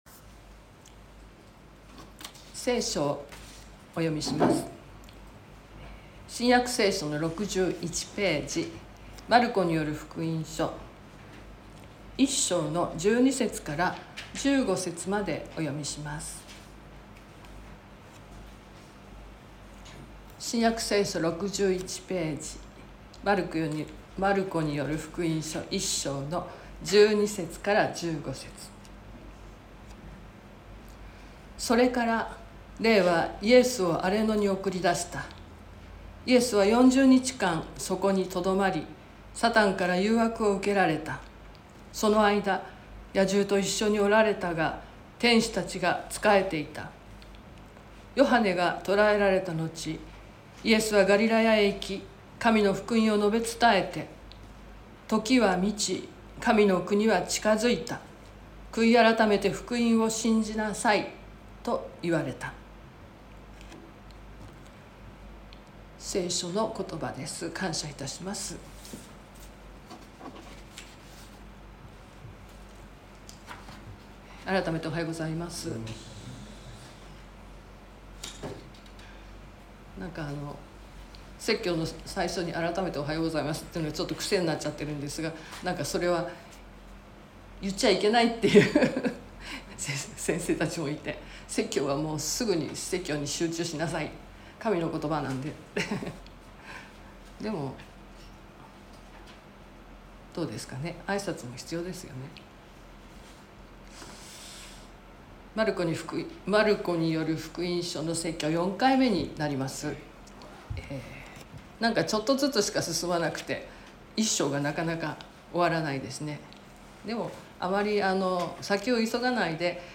説教アーカイブ。
Youtubeで直接視聴する 音声ファイル 礼拝説教を録音した音声ファイルを公開しています。